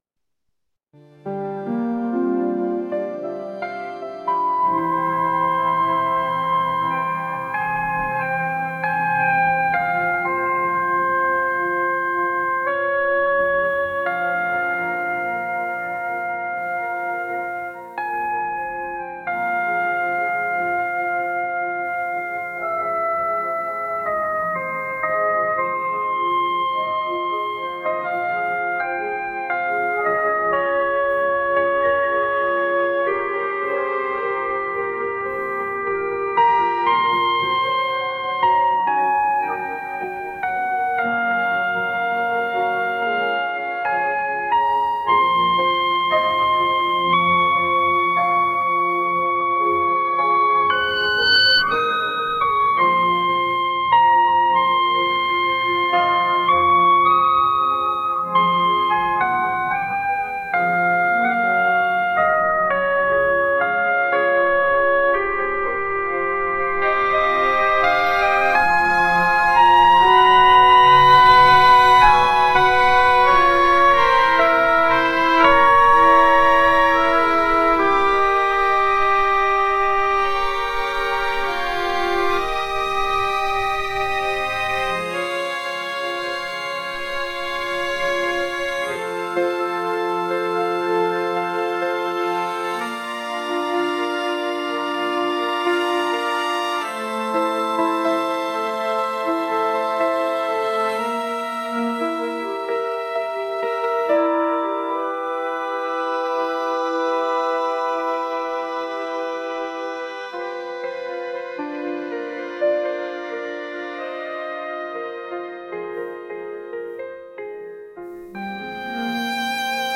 「優雅」
「幻想的」